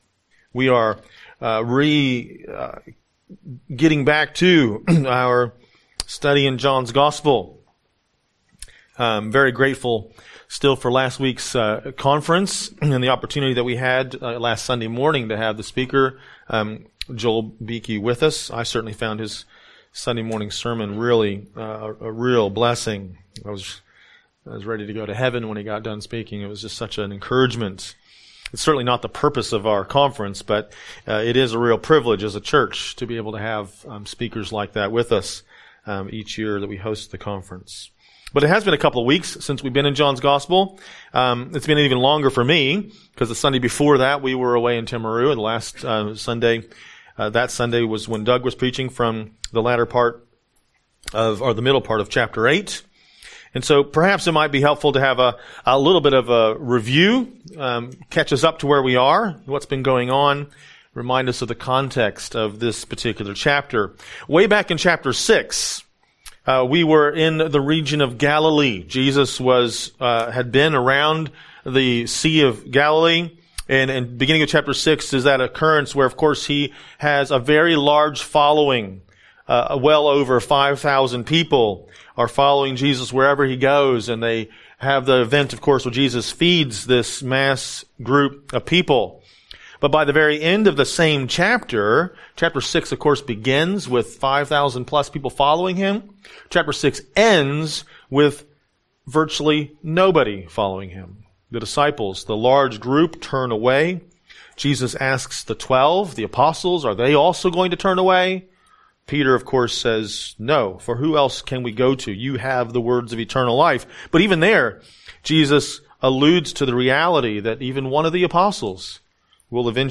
A message from the series "Mark."